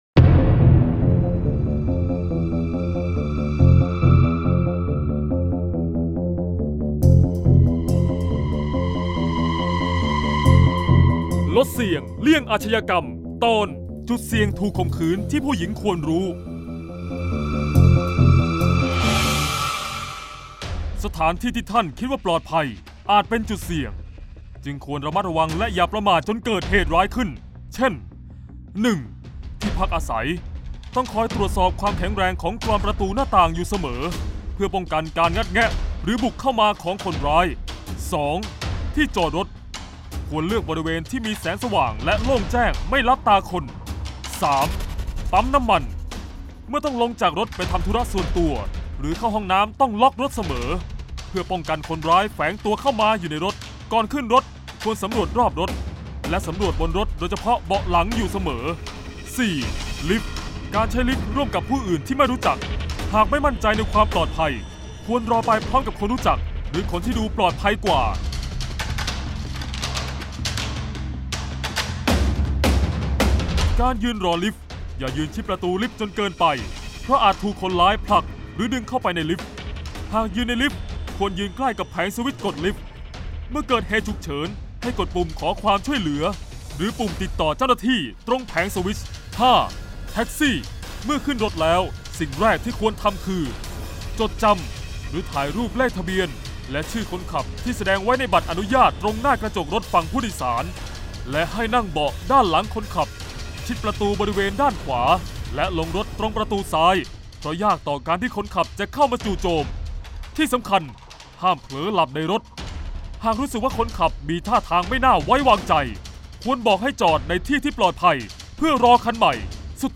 เสียงบรรยาย ลดเสี่ยงเลี่ยงอาชญากรรม 13-จุดเสี่ยงถูกข่มขืน